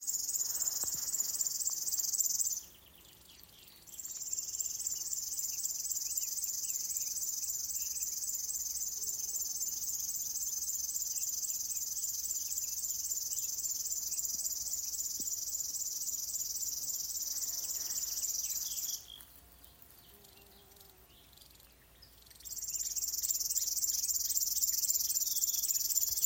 Upes ķauķis, Locustella fluviatilis
Administratīvā teritorijaAlūksnes novads
StatussDzied ligzdošanai piemērotā biotopā (D)